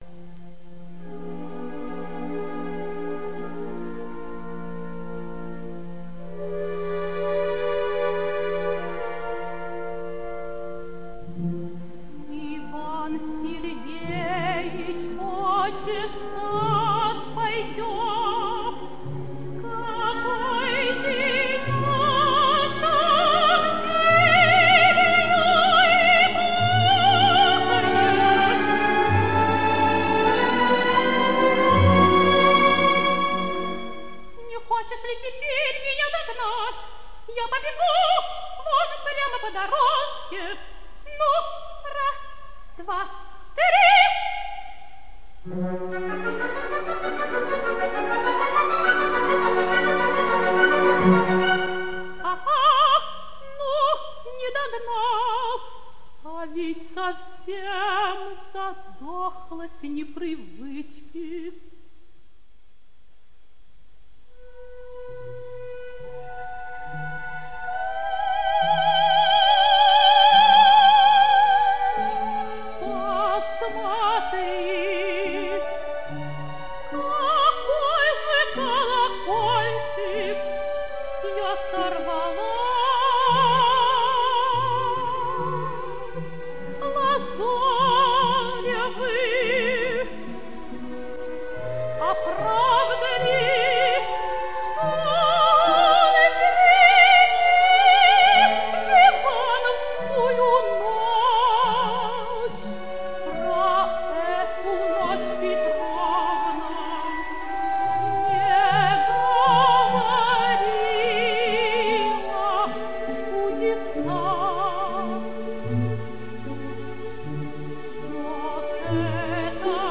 Обладала гибким выразительным голосом (лирическое сопрано), «ровным и наполненно звучащим во всех регистрах, на редкость серебристого тембра», великолепной филировкой ( С. 90), драматическим темпераментом.
Исполняет М. П. Амиранашвили.